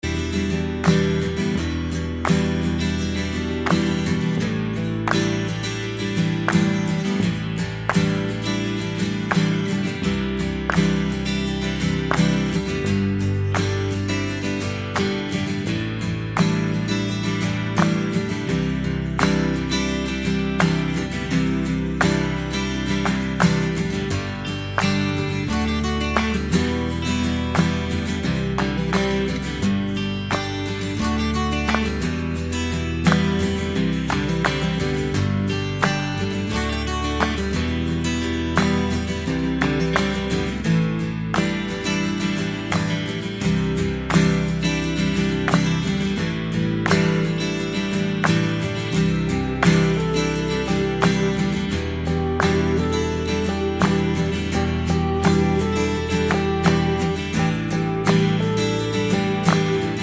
Minus Main Guitar Indie / Alternative 4:54 Buy £1.50